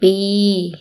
– bpii